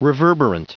Prononciation du mot : reverberant